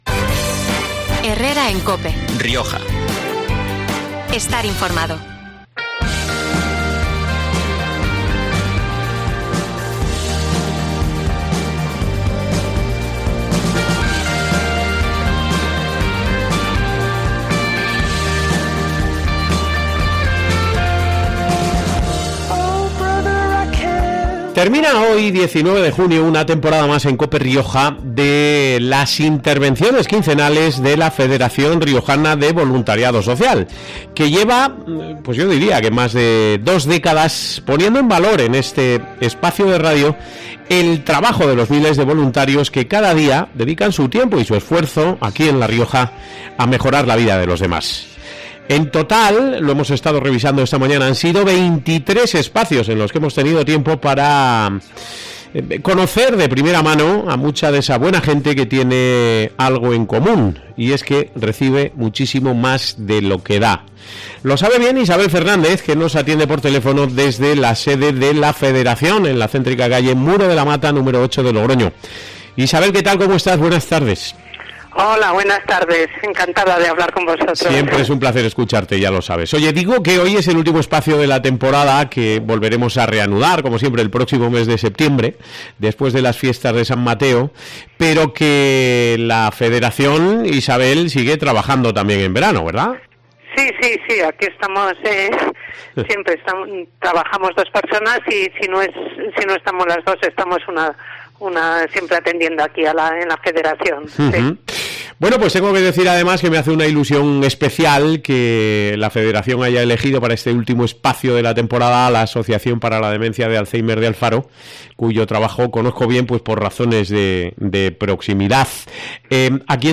Termina hoy, 19 de junio, una temporada más en COPE Rioja, de las intervenciones quincenales de la Federación Riojana de Voluntariado Social, que lleva más de dos décadas poniendo en valor en esta cadena de radio el trabajo de los miles de voluntarios que, cada día, dedican su tiempo y su esfuerzo a mejorar la vida de los demás.